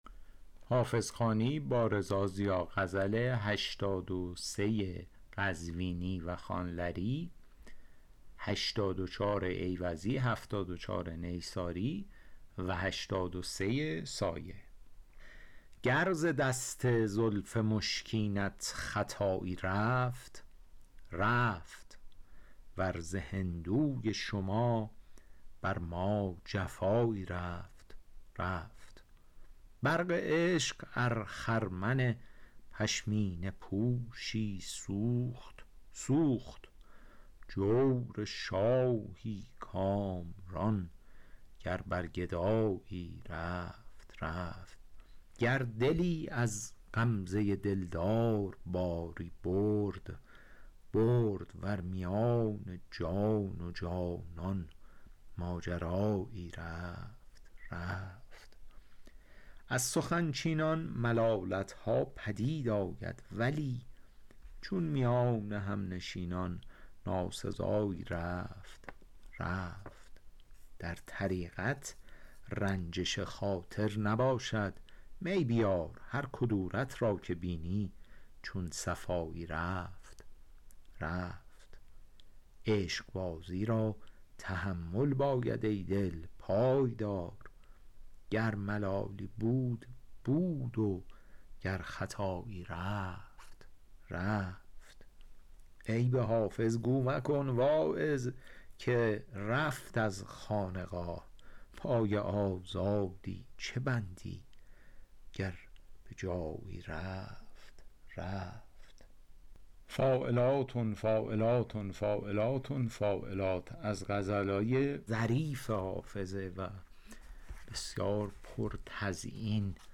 شرح صوتی